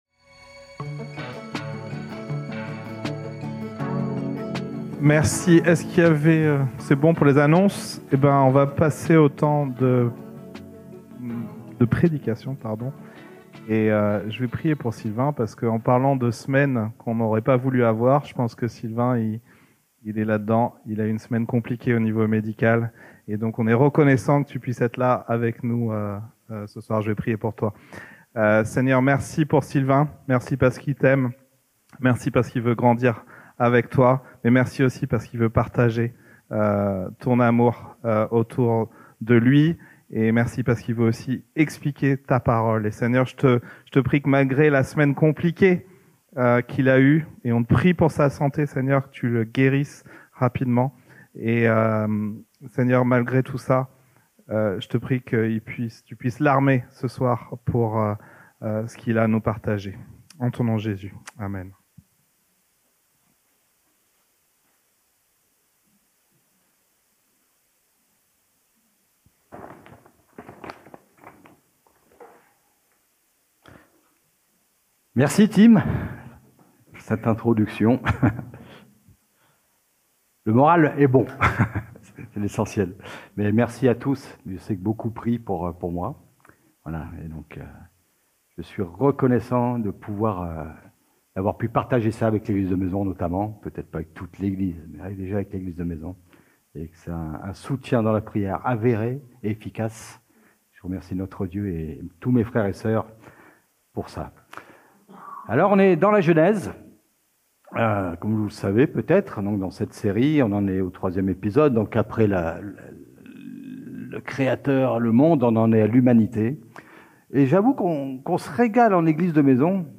Predication-2-2.mp3